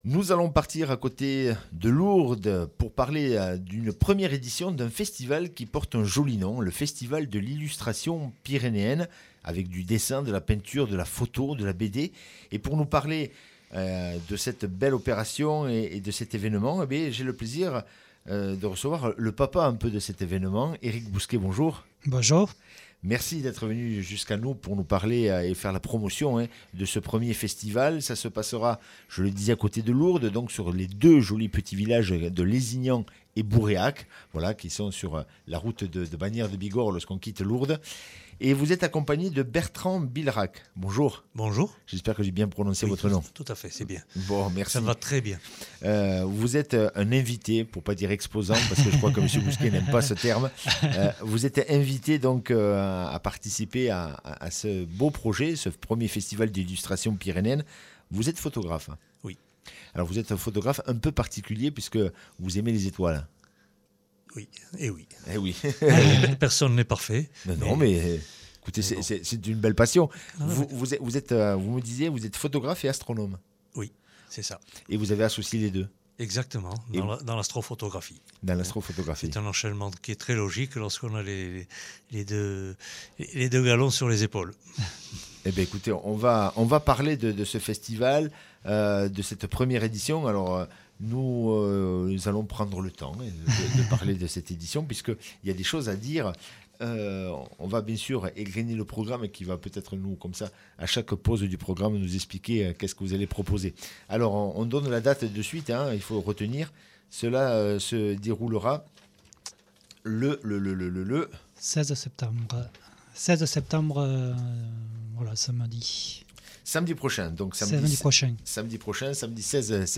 Accueil \ Emissions \ Information \ Locale \ Interview et reportage \ Lézignan/Bourréac : Festival de l’illustration pyrénéenne le 16 (...)